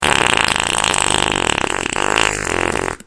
Funny Fart Sound Sound Button: Unblocked Meme Soundboard
Play the iconic Funny Fart Sound sound button for your meme soundboard!